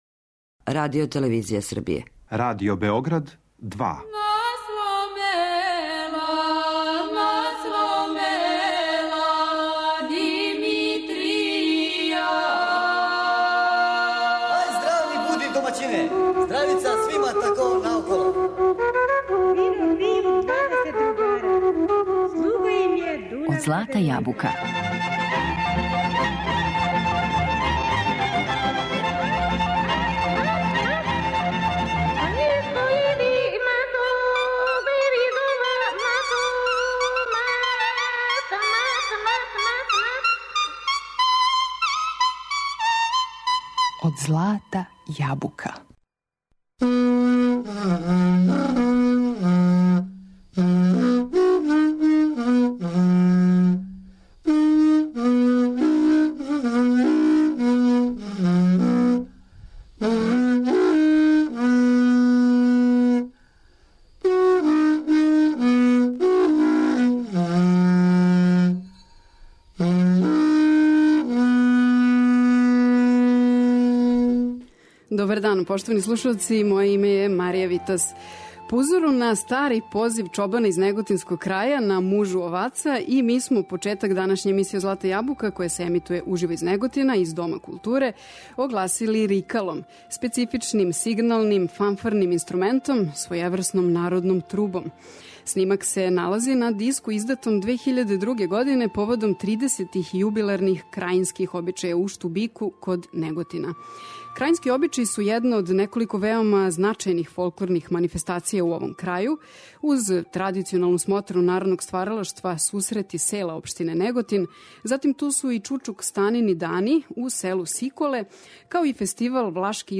Поводом обележавања 90 година Радио Београда, емисију ћемо реализовати уживо из Неготина.